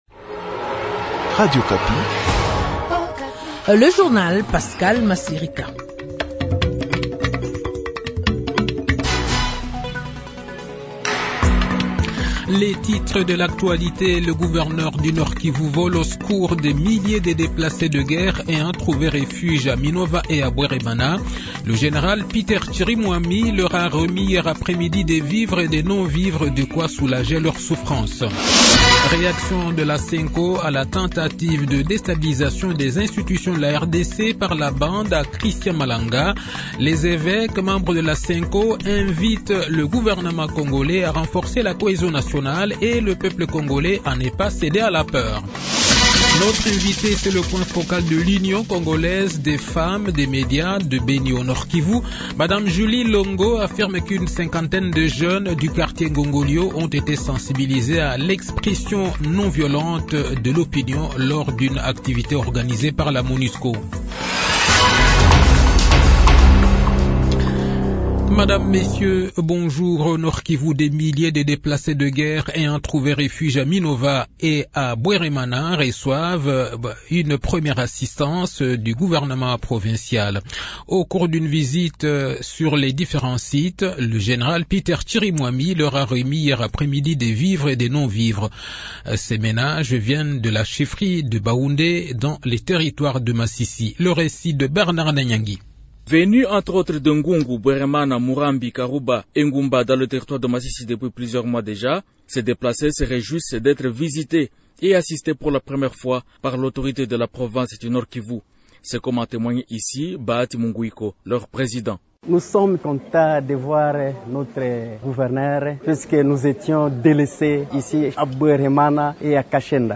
Journal de 15 h
Le journal de 15 h, 21 mai 2024